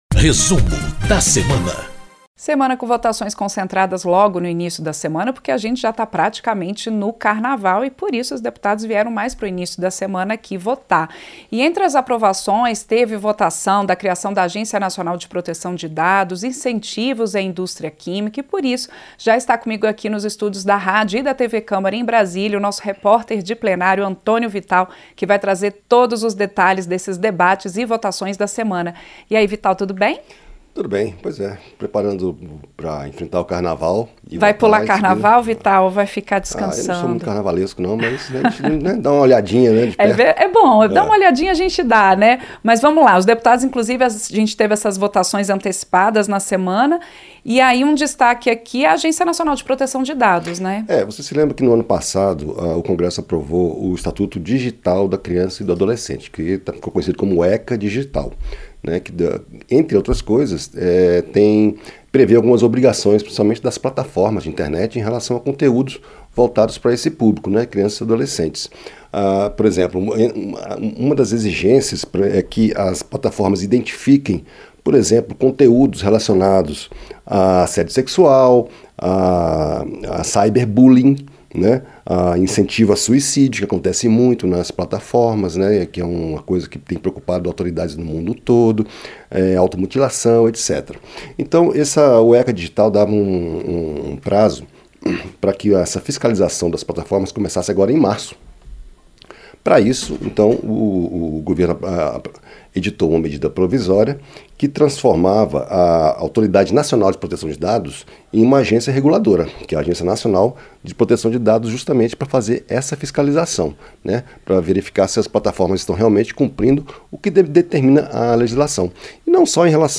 conversam sobre as votações na Câmara dos Deputados na semana que antecedeu o carnaval